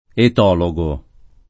[ et 0 lo g o ]